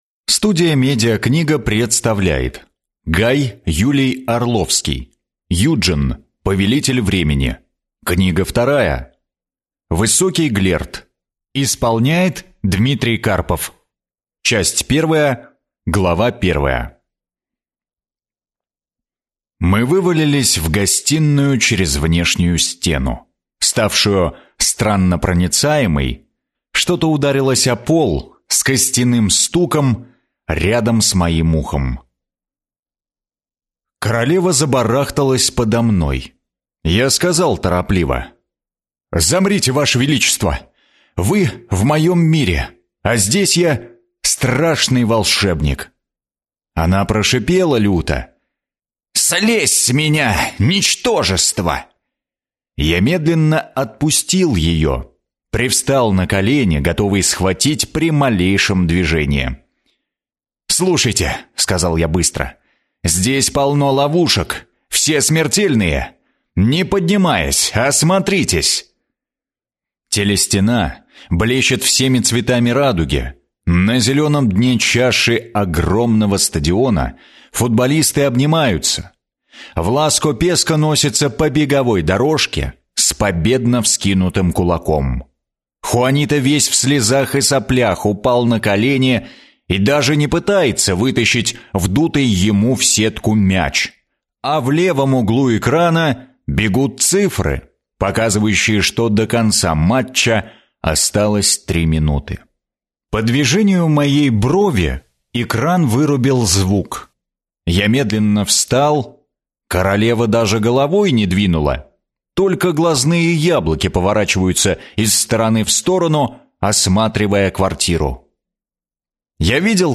Аудиокнига Высокий глерд | Библиотека аудиокниг